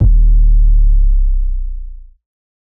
SOUTHSIDE_808_descent_C.wav